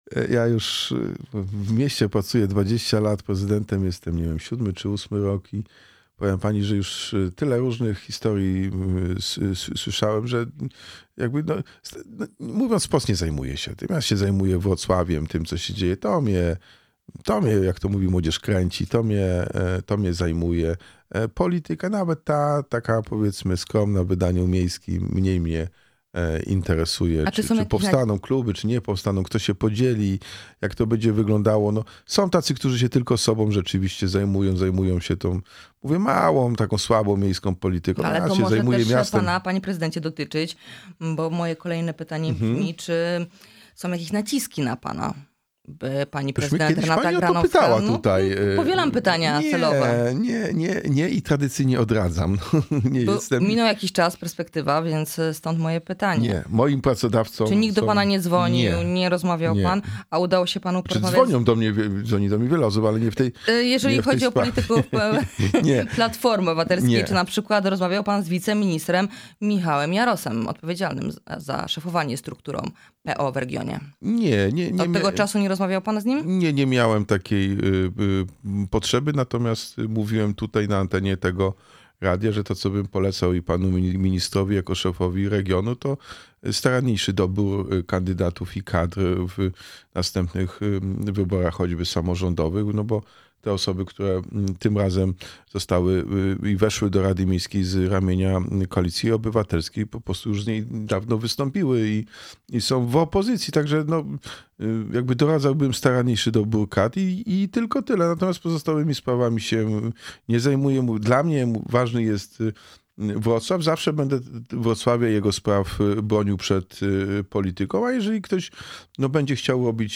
Jacek Sutryk – prezydent Wrocławia był naszym gościem.